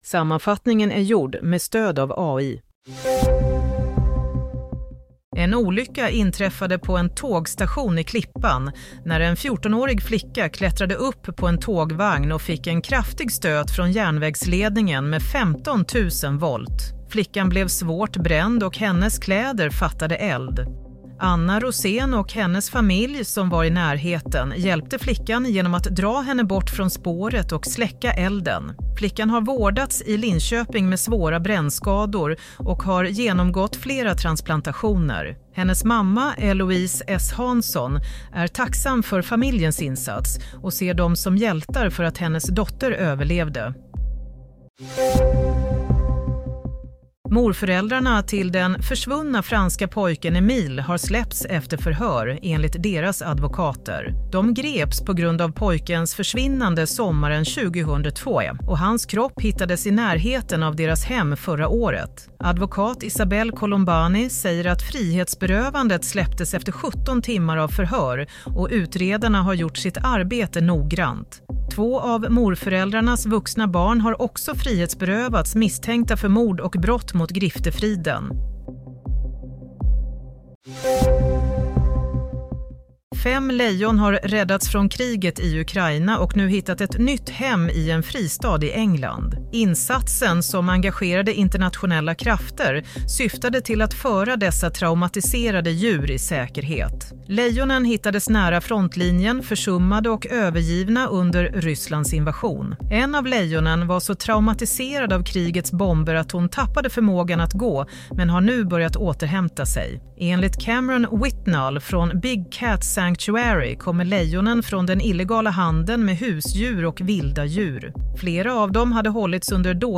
Nyhetssammanfattning - 27 mars 08:00